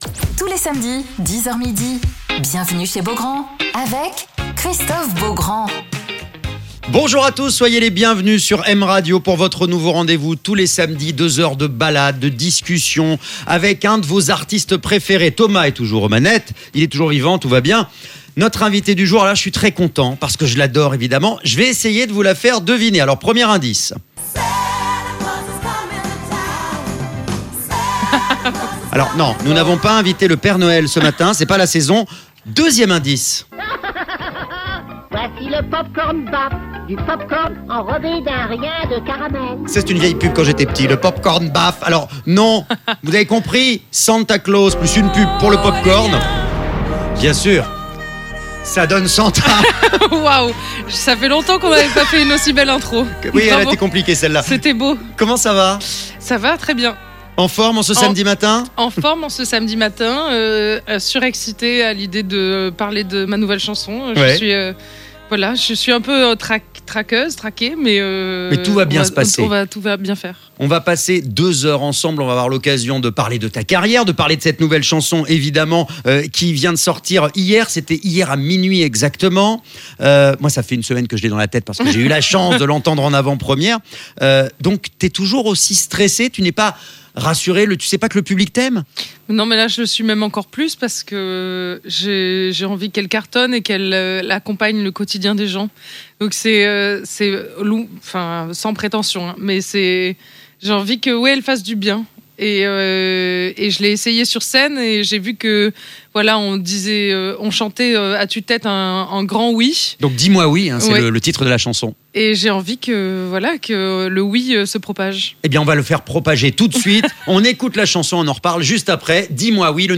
Alors qu'elle est de retour avec son nouveau titre "Dis moi" Santa est l'invitée de Christophe Beaugrand sur M Radio